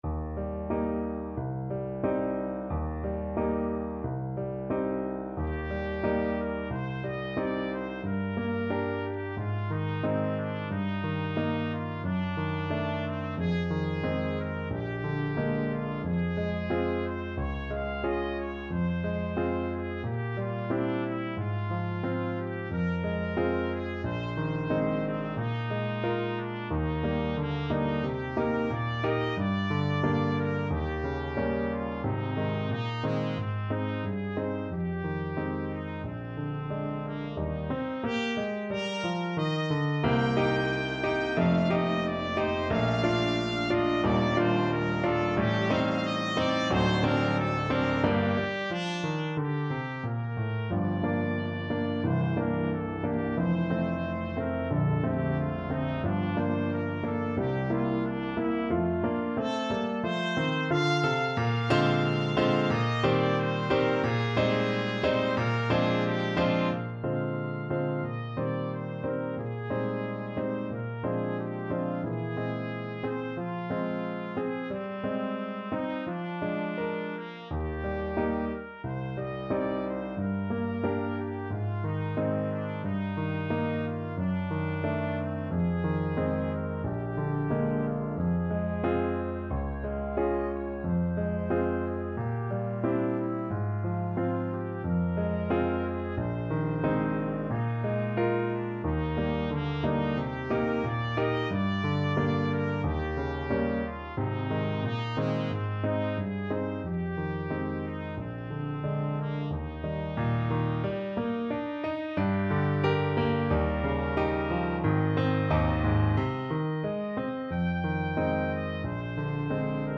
Trumpet
Eb major (Sounding Pitch) F major (Trumpet in Bb) (View more Eb major Music for Trumpet )
2/4 (View more 2/4 Music)
~ = 100 Allegretto con moto =90
Ab4-G6
Classical (View more Classical Trumpet Music)
bridge_spring_song_TPT.mp3